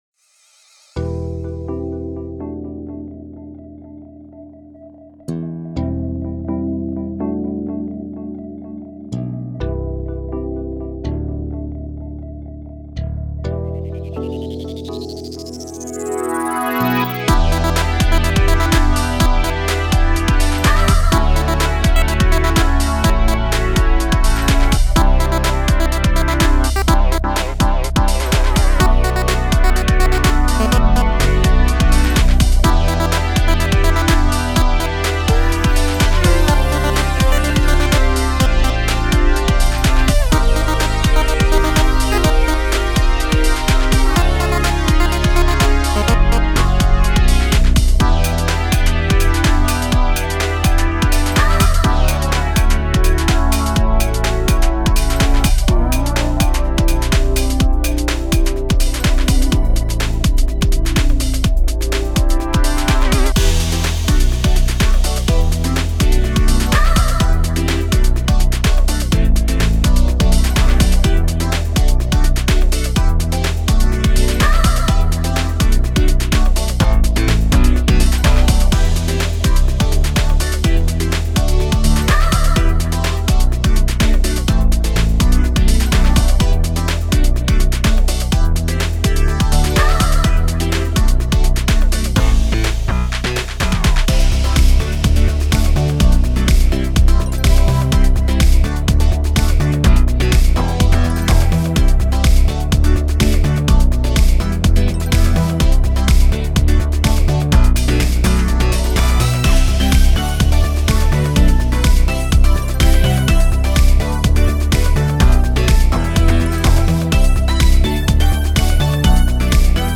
Style: Dance
This upbeat coop